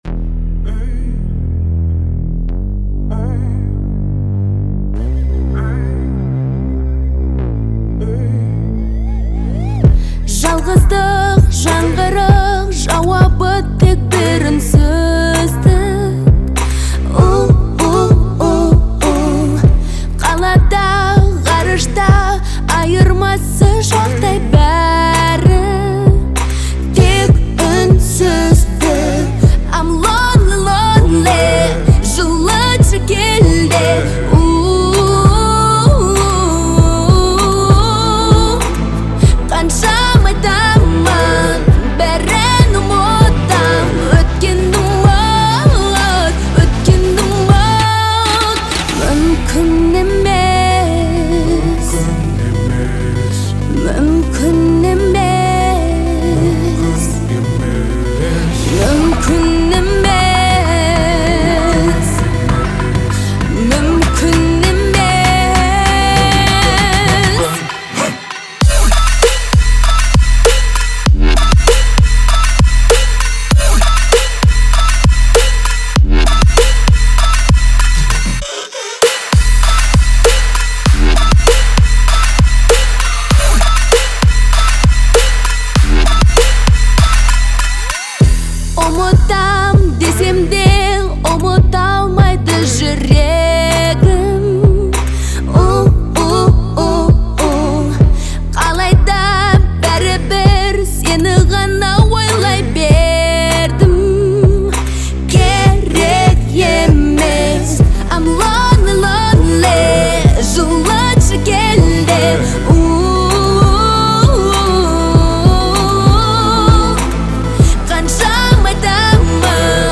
демонстрирует свой уникальный вокал и эмоциональную подачу